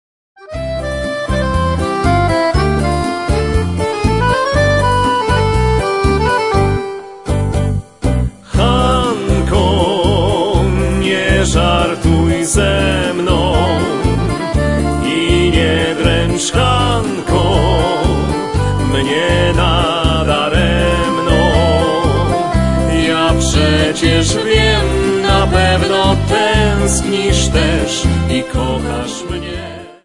Folk Tangos and Waltzes.